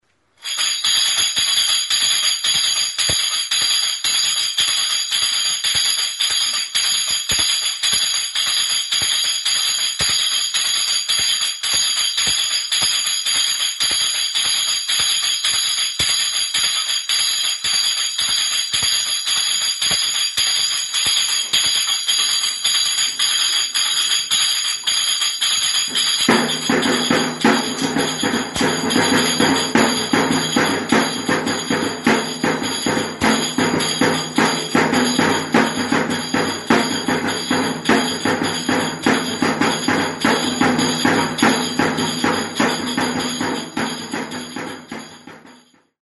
Enregistré avec cet instrument de musique.
EUROPE -> GAZTELA-MANTXA
SONAJAS; SONAJA DOBLE
Idiophones -> Frappés -> Indirectement
Eragiteko heldulekua du eta hots emateko metalezkobi txinda-ilara ditu.